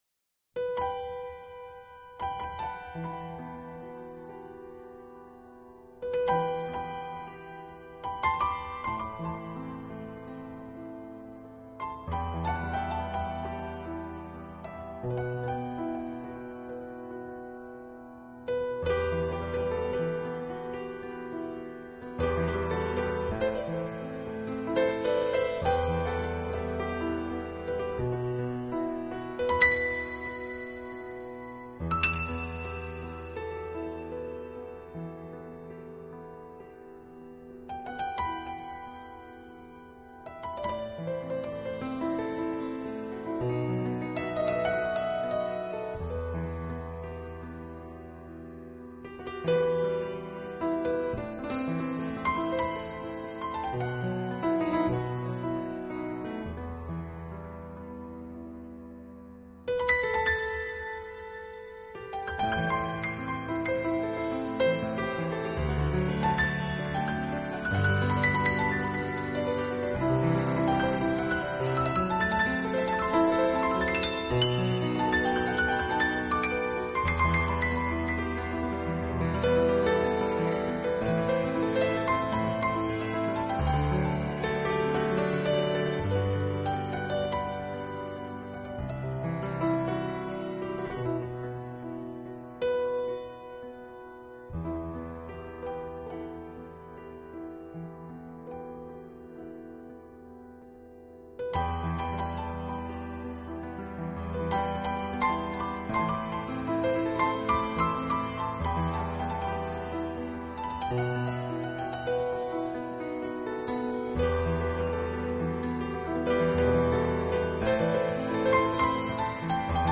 Genre..........: newage